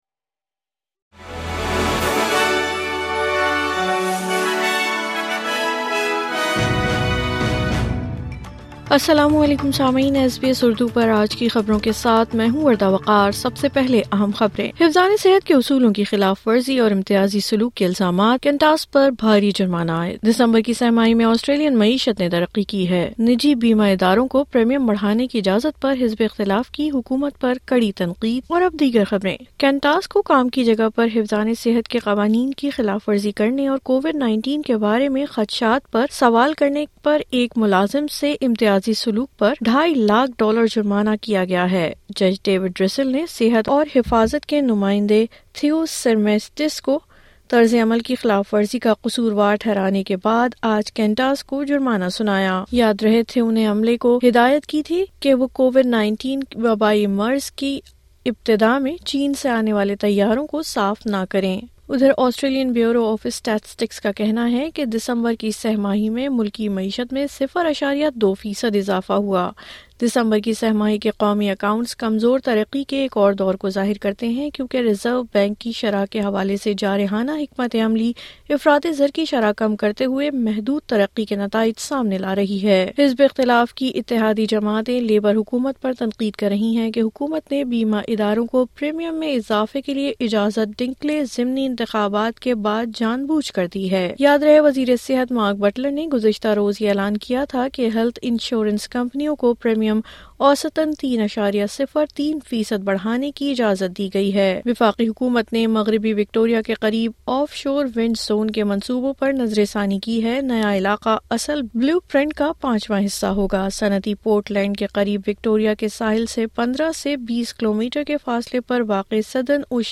حفظان صحت کے اصولوں کی خلاف ورزی اور امتیازی سلوک کے الزامات ، کنٹاس پر بھاری جرمانہ عائد , دسمبر کی سہ ماہی میں آسٹریلین معیشت نے ترقی کی ہے ,نجی بیمہ اداروں کو پریمیم بڑھانے کی اجازت پر حزب اختلاف کی حکومت پر کڑی تنقید. مزید تفصیل کے لئے سنئے اردو خبریں